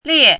怎么读
liè
lie4.mp3